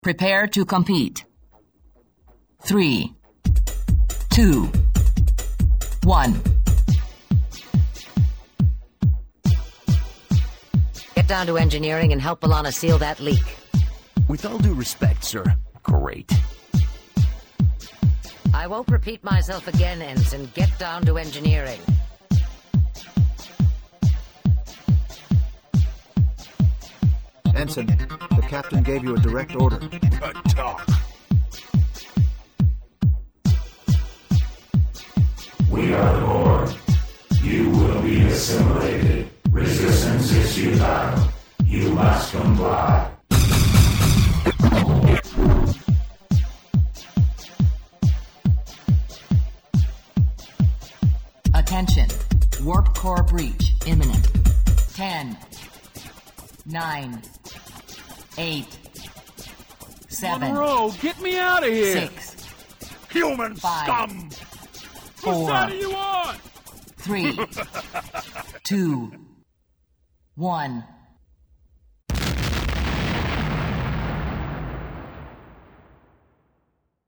Hudební remix